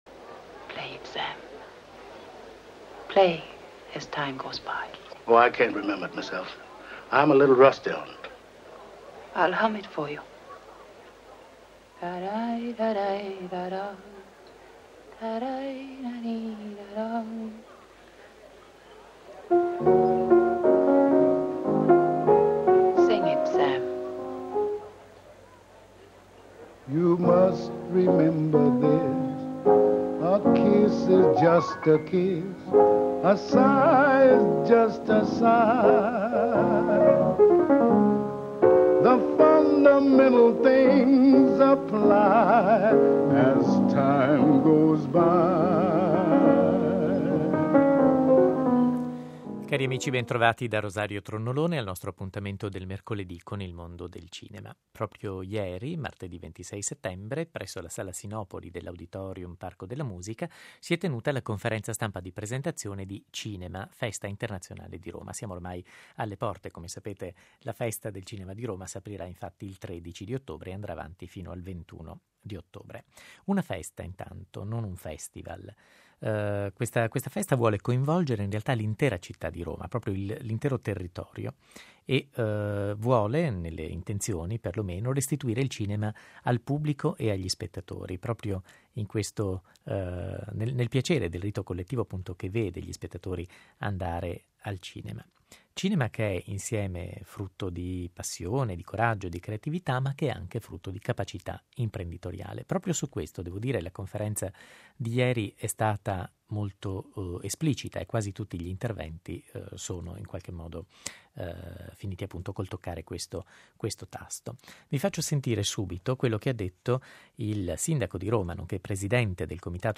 Il sindaco della capitale, Walter Veltroni, descrive a 105 Live le varie sezioni e gli appuntamenti più importanti de Festival.